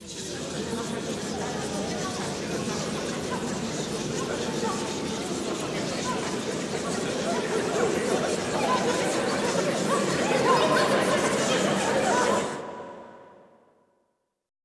Index of /90_sSampleCDs/Best Service - Extended Classical Choir/Partition I/VOICE ATMOS